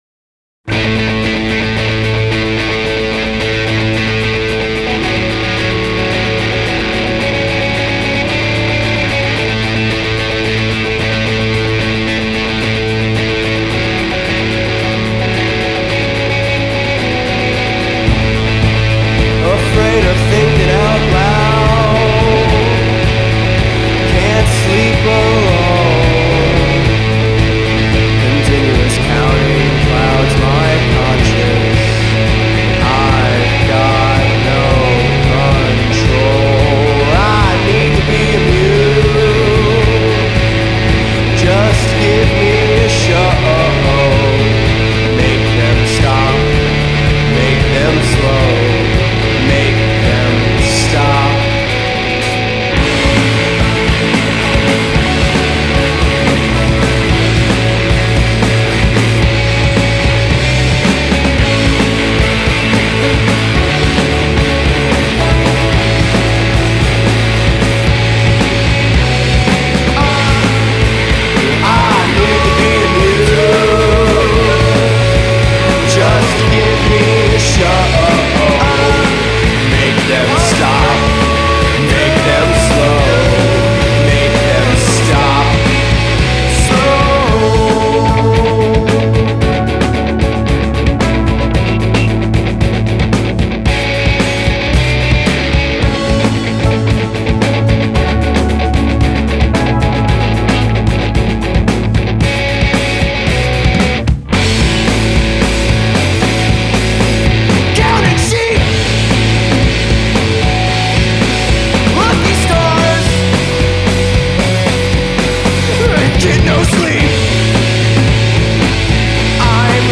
guitar
keys / trombone / vocals
drums
bass / bass synth / vocals